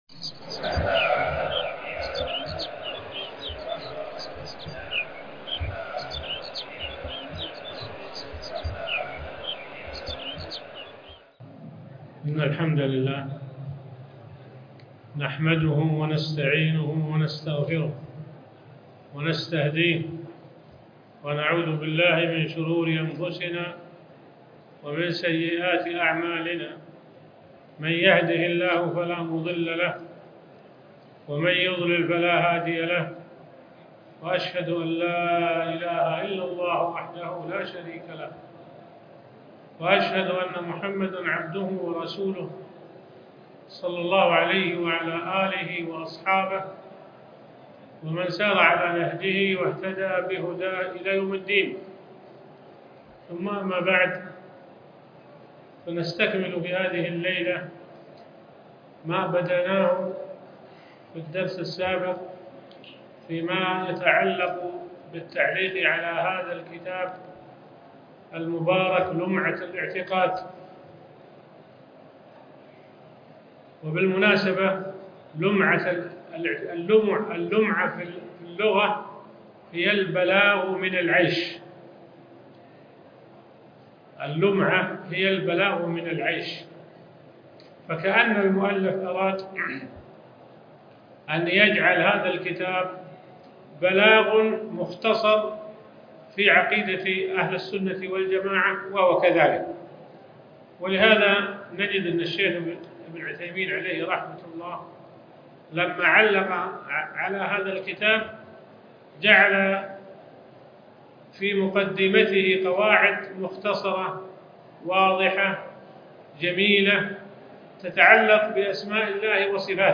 الدرس 2( شرح لمعة الاعتقاد)